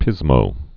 (pĭzmō)